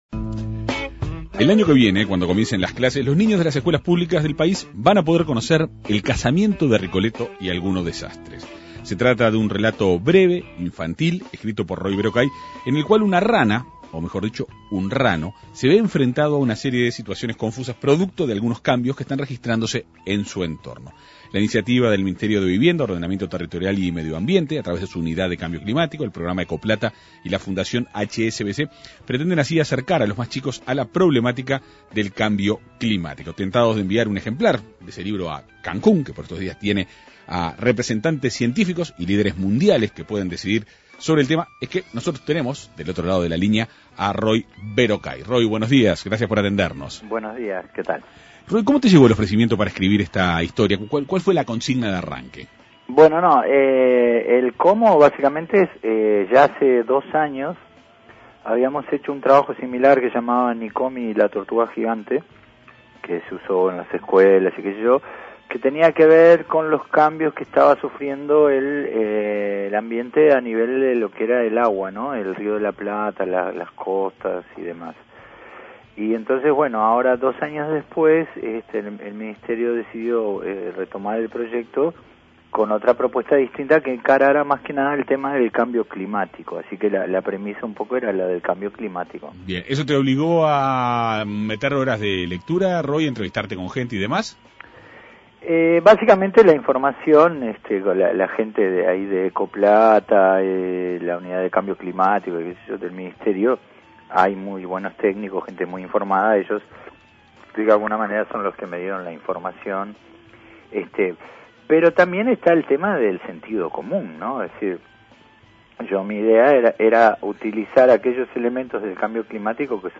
Se trata de un breve relato infantil escrito por Roy Berocay. El escritor conversó en la Segunda Mañana de En Perspectiva.